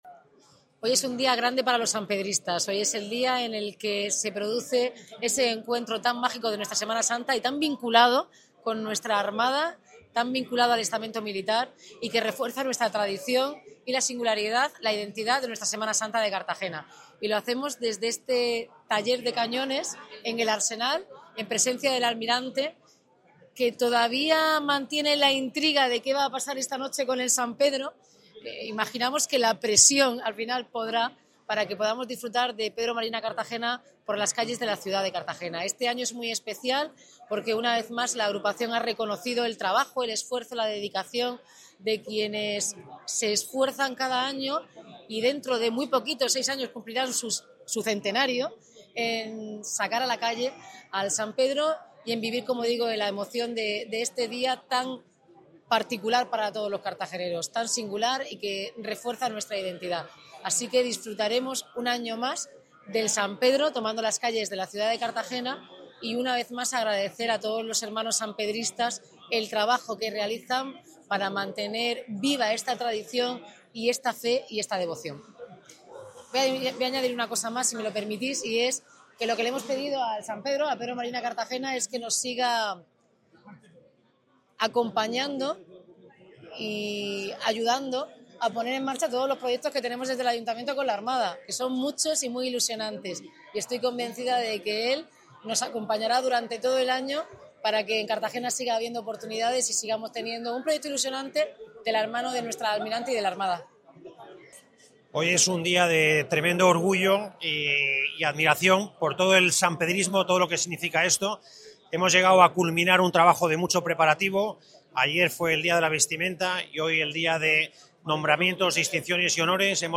Enlace a Declaraciones de la alcaldesa, Noelia Arroyo, y el almirante del Arsenal, Alejandro Cuerda, acto de nombramientos del San Pedro
La alcaldesa de Cartagena, Noelia Arroyo, ha sido nombrada Hermana de Honor de la Agrupación de San Pedro Apóstol durante el tradicional acto de entrega de distinciones celebrado este Martes Santo en el Taller de Cañones del Arsenal Militar.